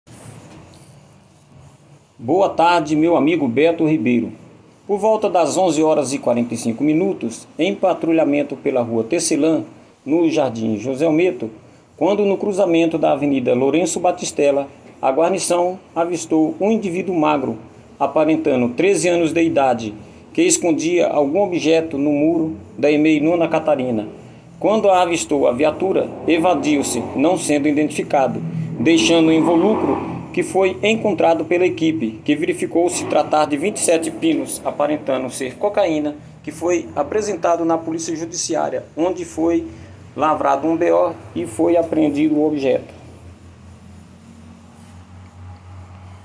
O caso foi apresentado na Central de Polícia Judiciária.